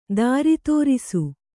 ♪ dāri tōrisu